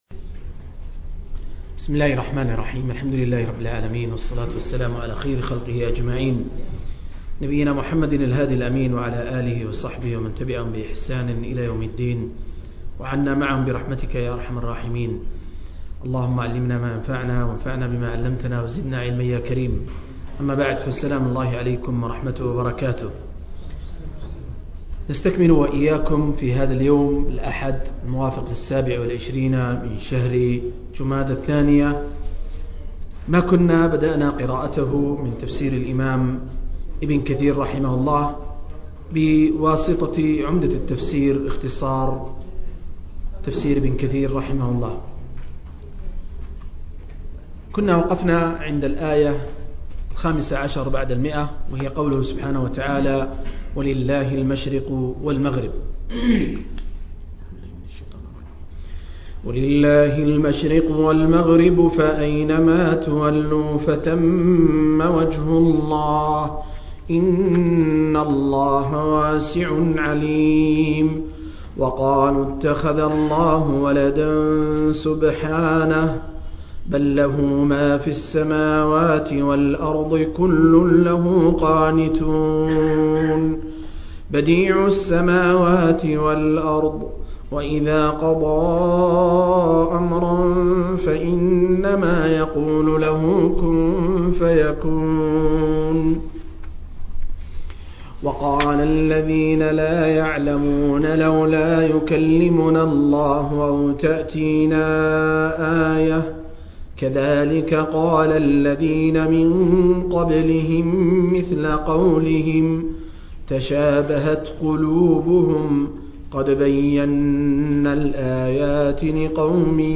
026- عمدة التفسير عن الحافظ ابن كثير – قراءة وتعليق – تفسير سورة البقرة (الآيات 115-119)